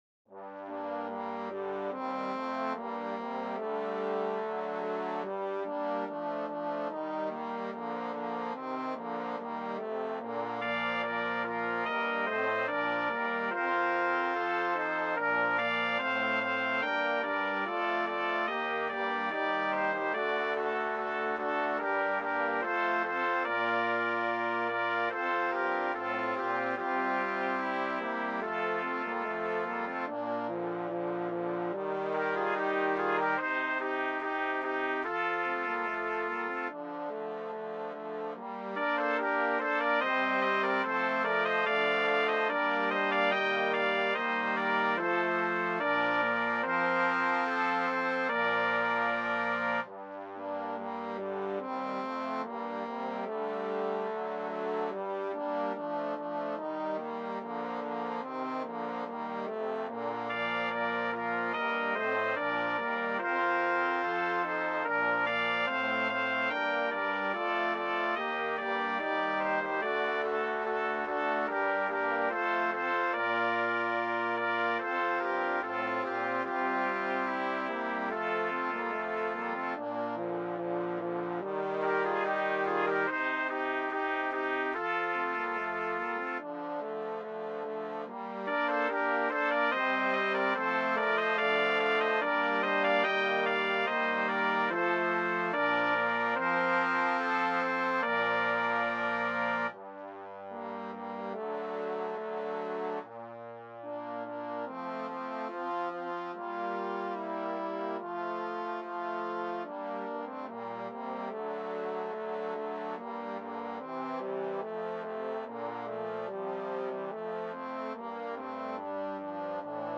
BRASS QUINTET
STANDARD BRASS QUINTET